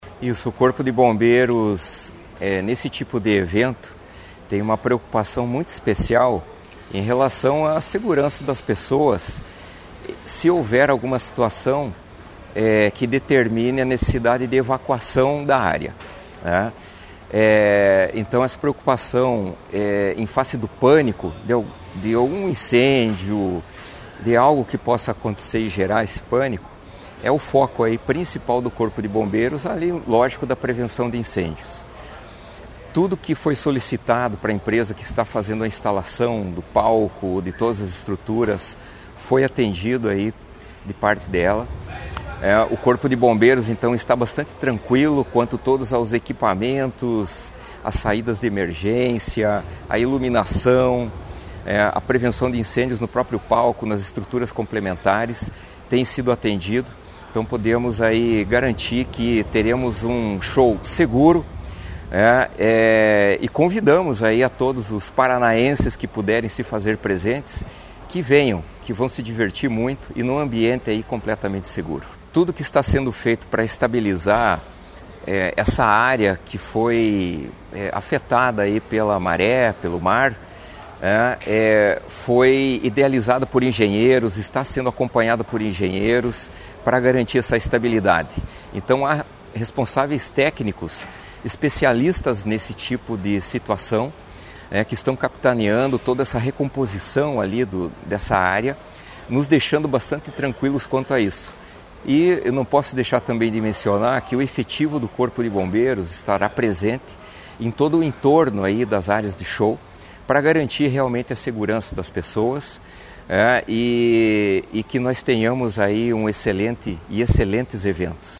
Sonora do comandante-geral do Corpo de Bombeiros Militar, coronel Antônio Hiller, sobre a manutenção dos shows do Verão Maior em Caiobá